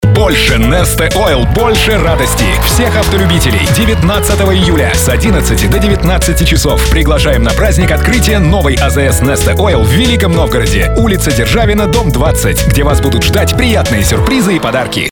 Примеры информационных аудиороликов
Классические “дикторские” аудиоролики с простым, информационным текстом. Озвучиваются, одним диктором, или двумя, разных полов.
Радиореклама Несте,
Аудиообъявление Neste